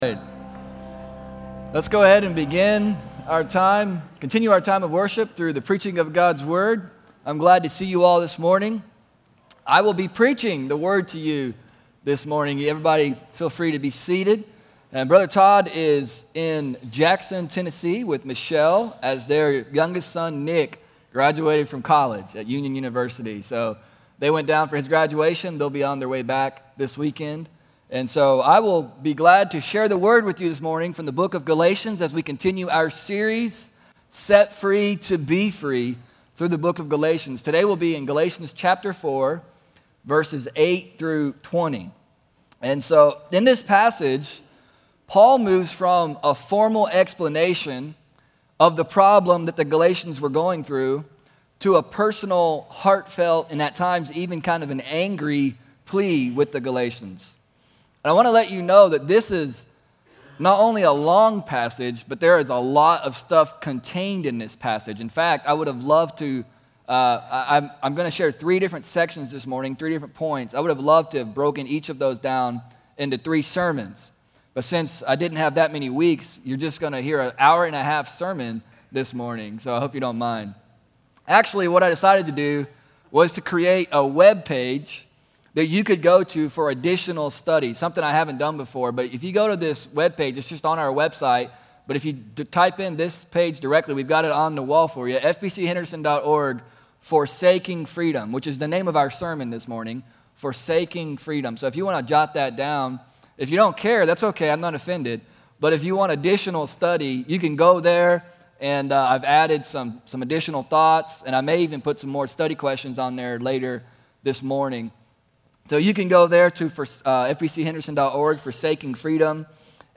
I’ve actually cut a lot out of my sermon, but I felt like there was some important stuff to cover.